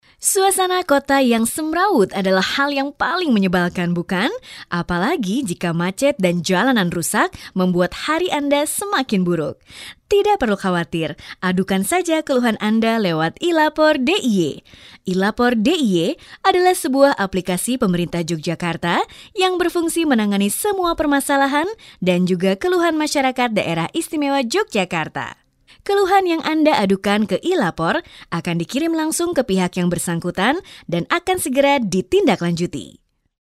游戏-动画角色